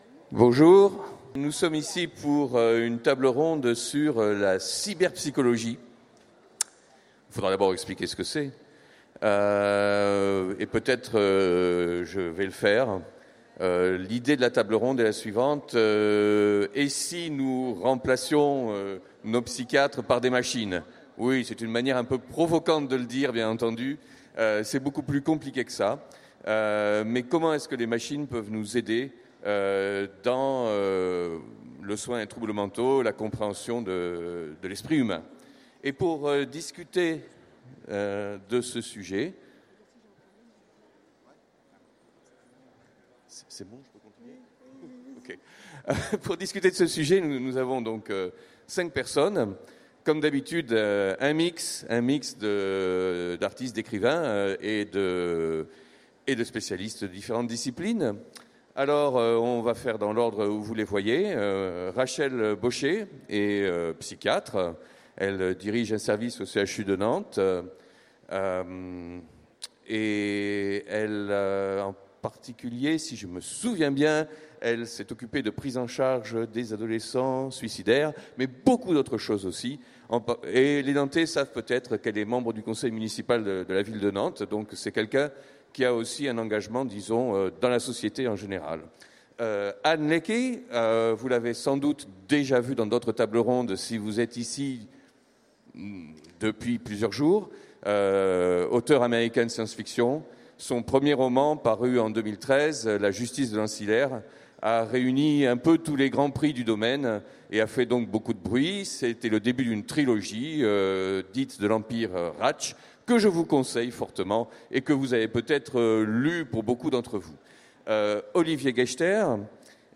Utopiales 2016 : Conférence Vers une cyberpsychologie